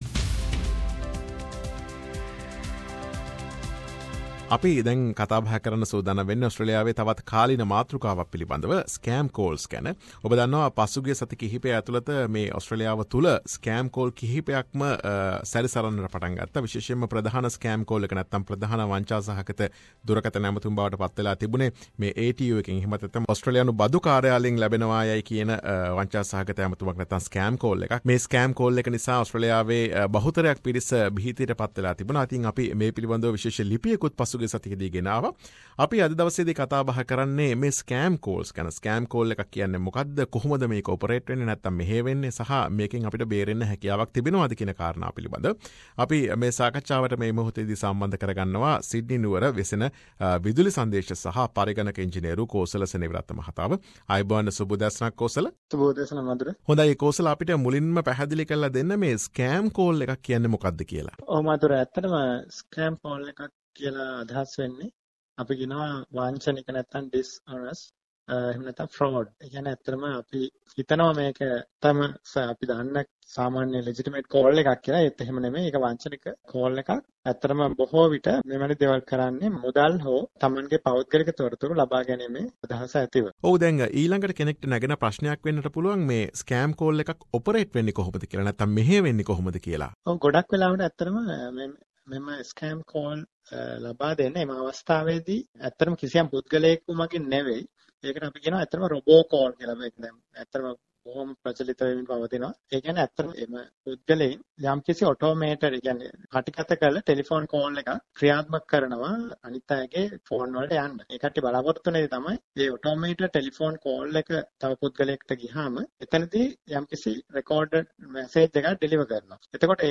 සාකච්ඡාව.